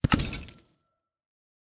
Minecraft Version Minecraft Version latest Latest Release | Latest Snapshot latest / assets / minecraft / sounds / block / vault / eject2.ogg Compare With Compare With Latest Release | Latest Snapshot
eject2.ogg